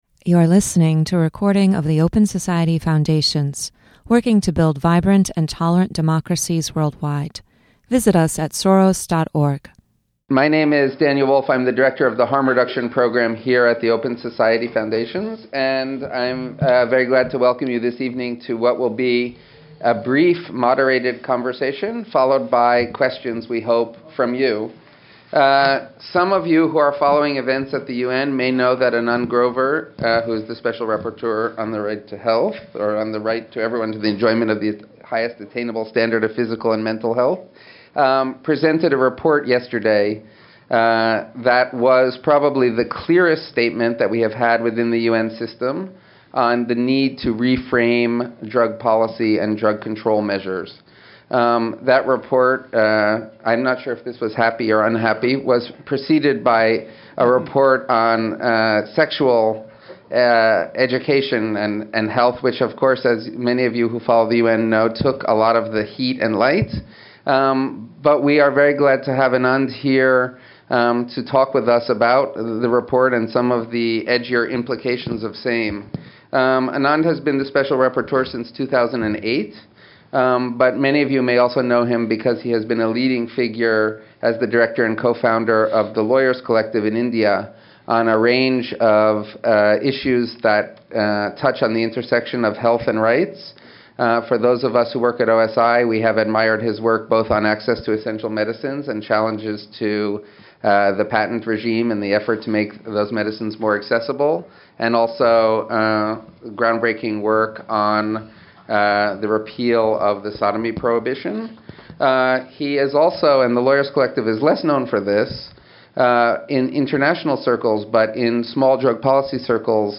The Open Society Foundations and Human Rights Watch present a moderated discussion with UN Special Rapporteur Anand Grover on the issue of drug control and global health.